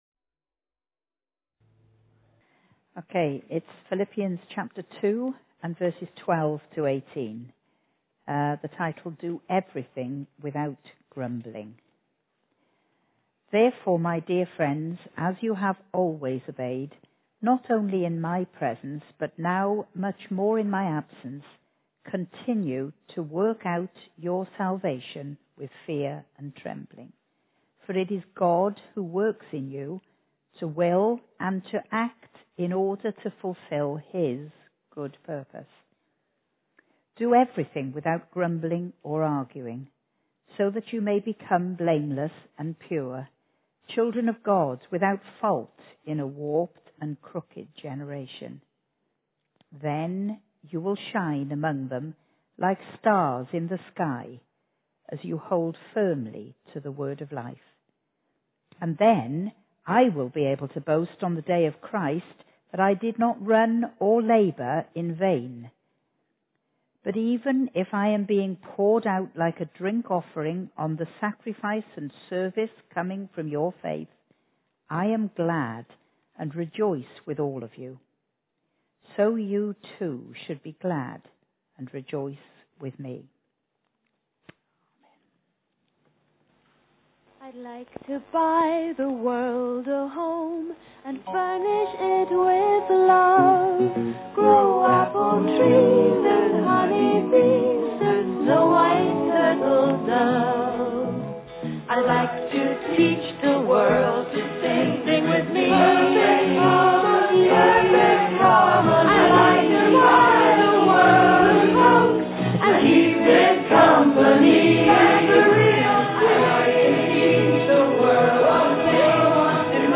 Genre: Speech.